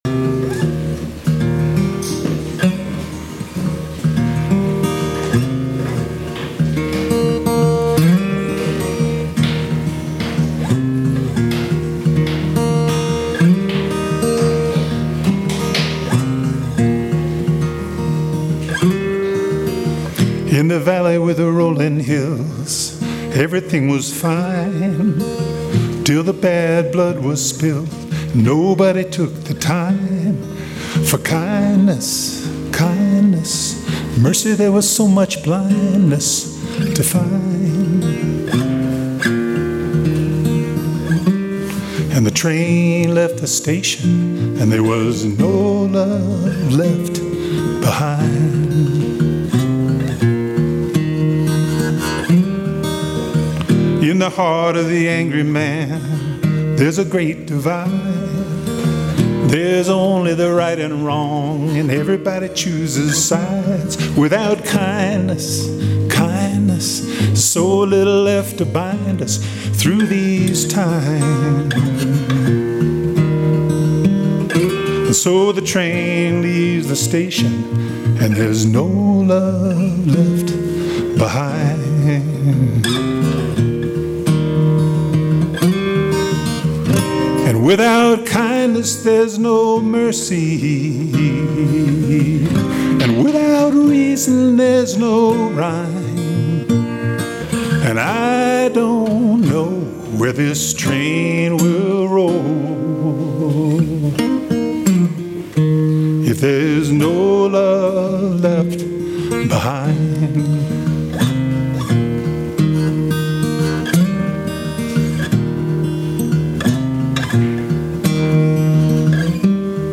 Kindness - Musical Performance